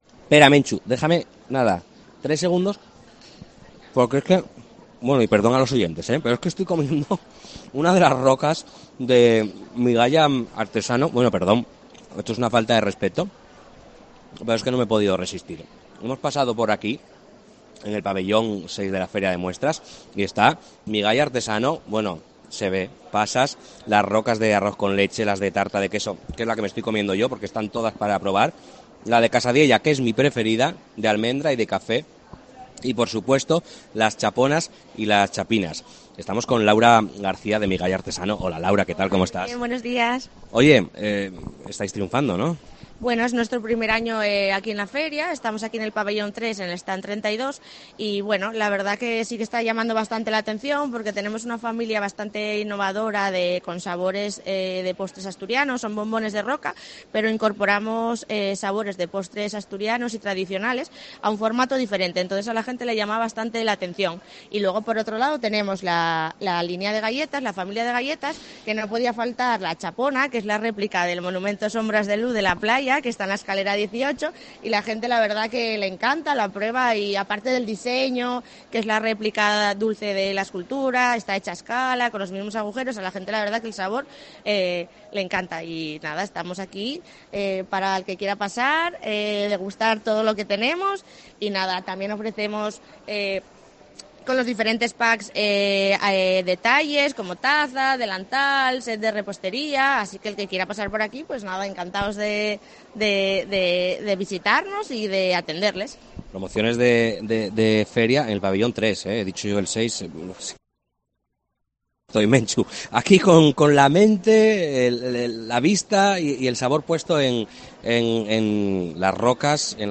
FIDMA 2023: entrevista